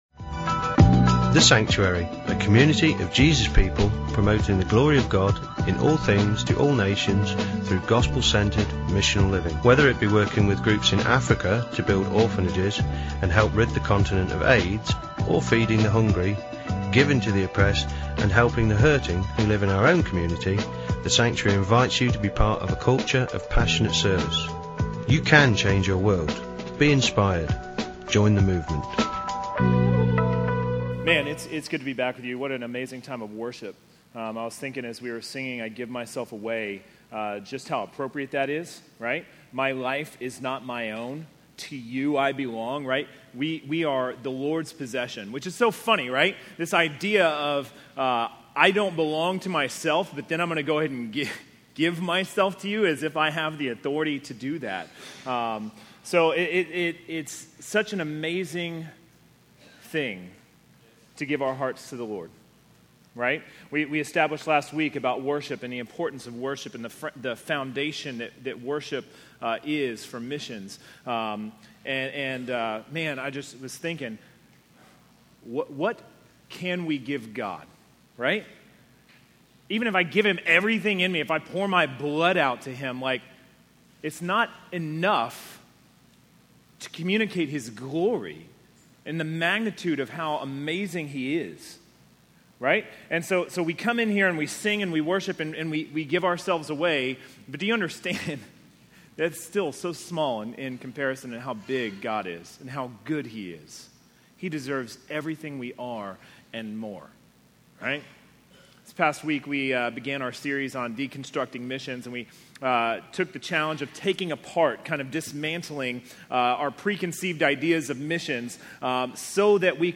A message from the series "Deconstructing Missions."